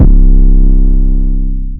MZ 808 [Drill].wav